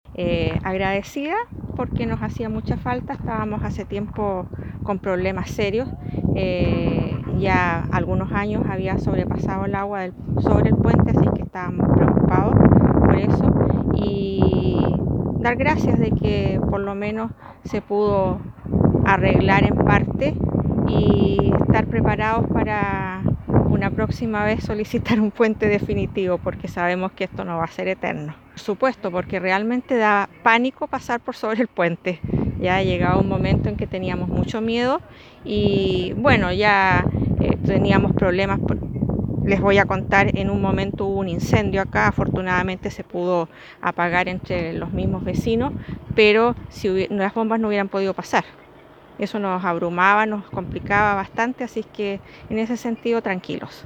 Durante el pasado fin de semana se realizó la ceremonia de inauguración de la reposición del Puente San Francisco, un anhelado proyecto por parte de los vecinos del sector distante a 8 kilómetros de Osorno por la Ruta 5 hacia el norte.